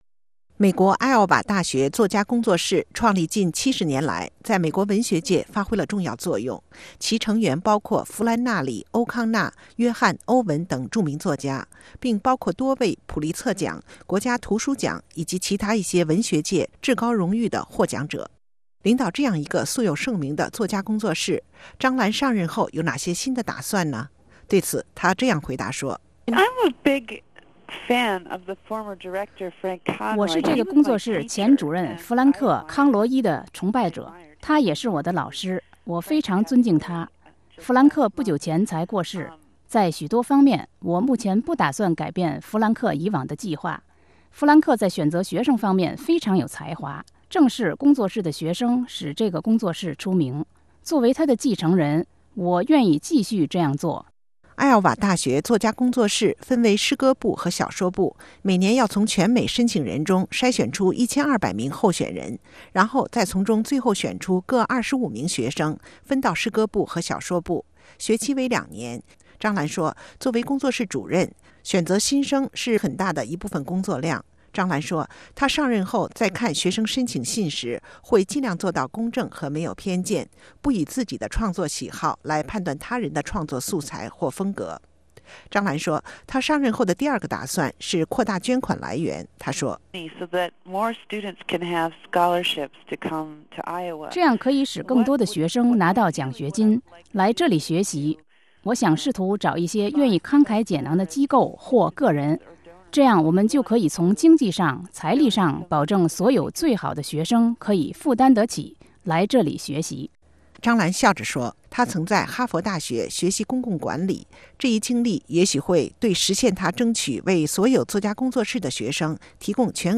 But as she told RFA's Mandarin service in a recent interview, Chang prefers to leave personal attributes behind when it comes to a writer's work.